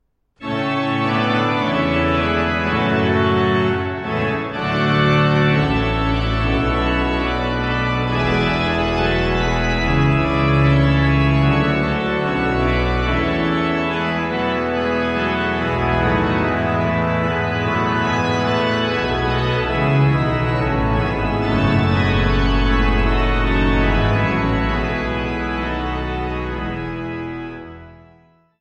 zweimanualigen Instrument